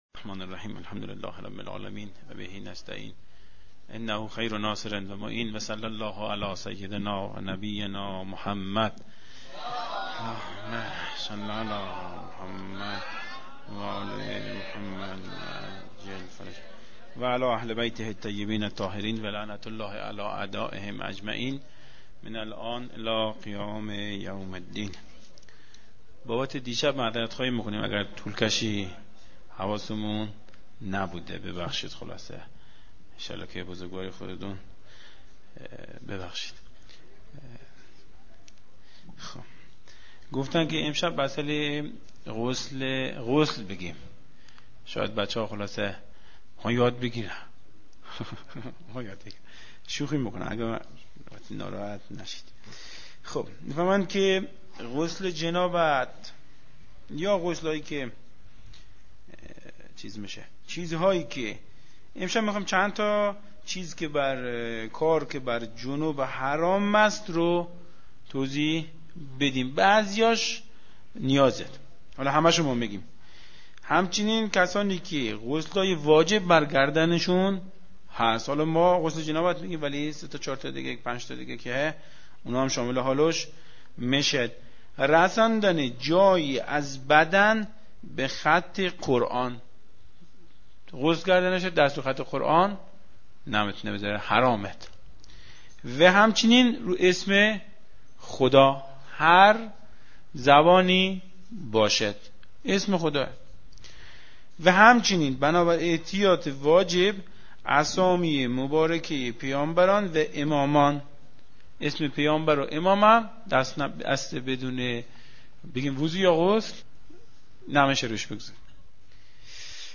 بیان احکام